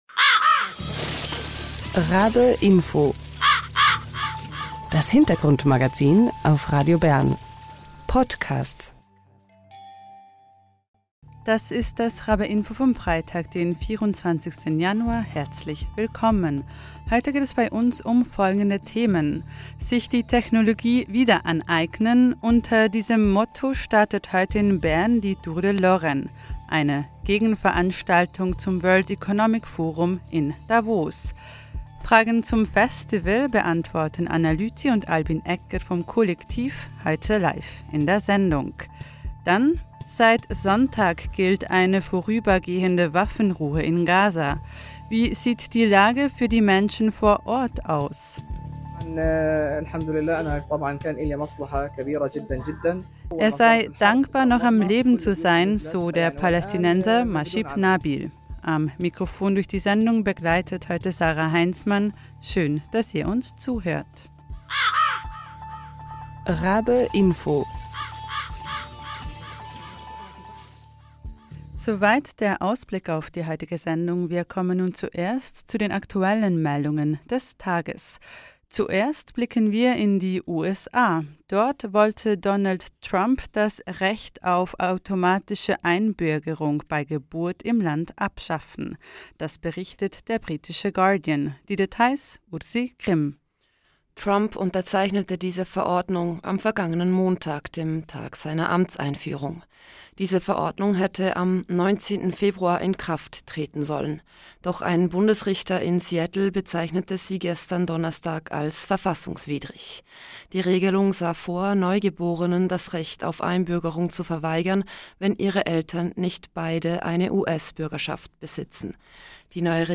live in der Sendung.